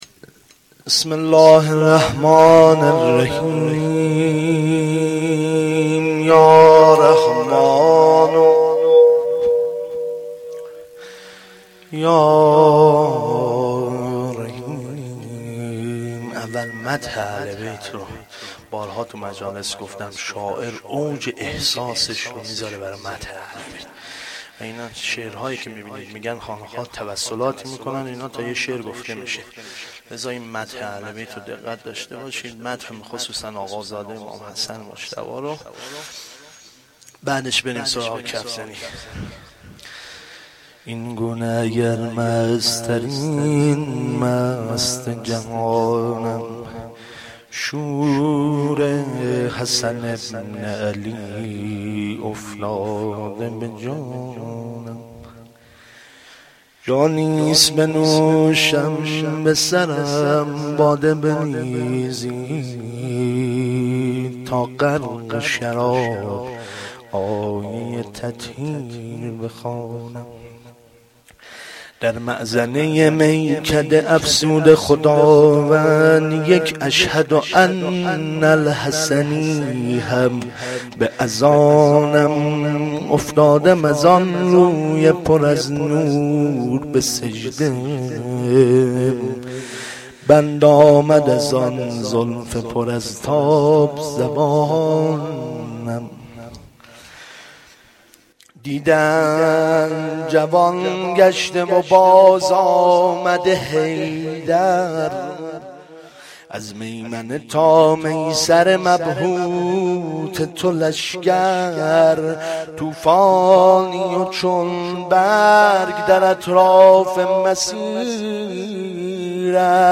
مدح
madh-Rozatol-Abbas.Milad-Emam-Hasan.mp3